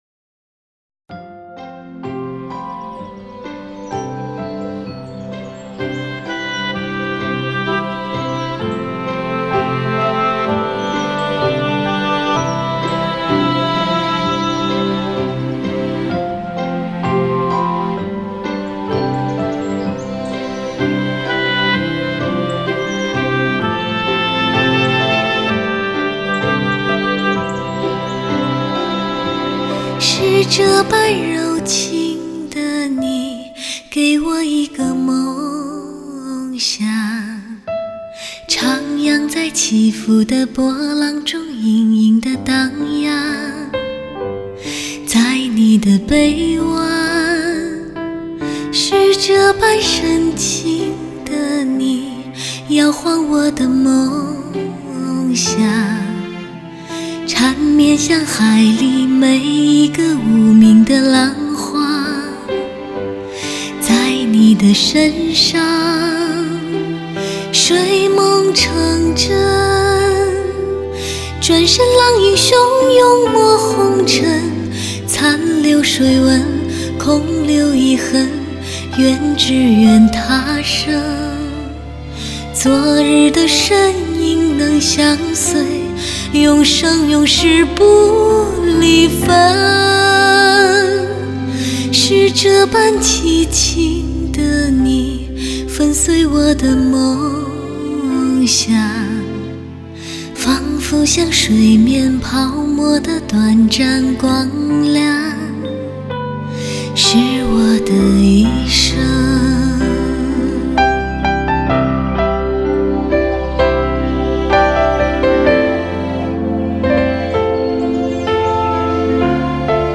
Жанр: Modern Traditional / Chinese Pop